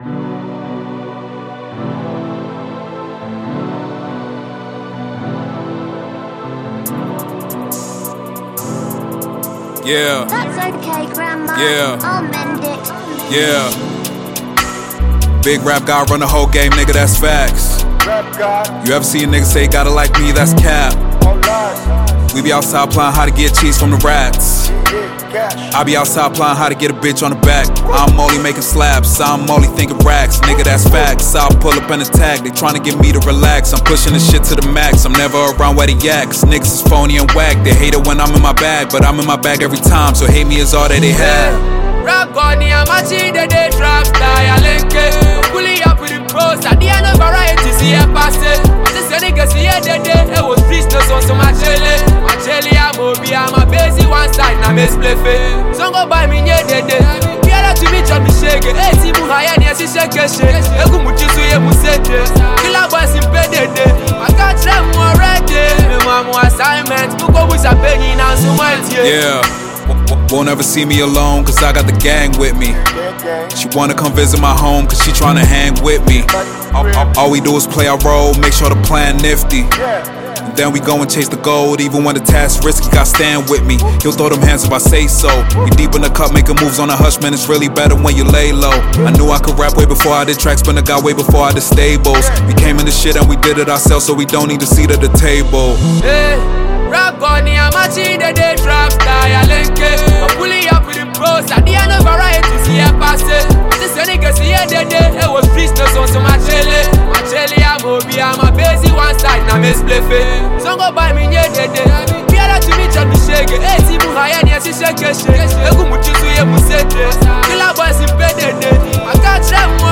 rap record